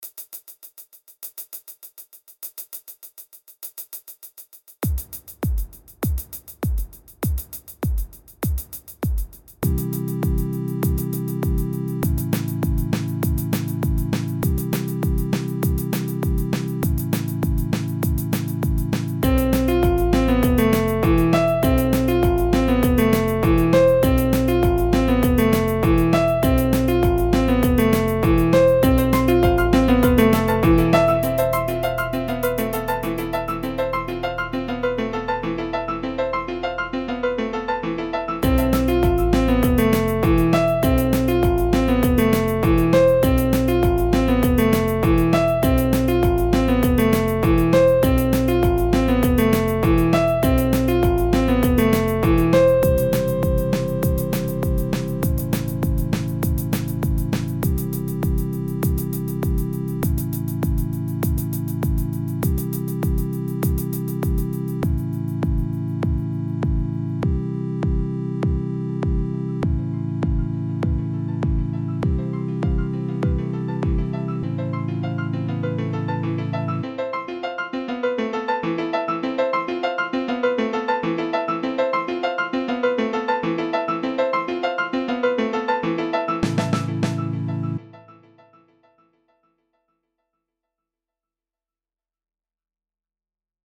Up and Down - just wanted to show that I could use a different main instrument, and well this fun and funky little song came together.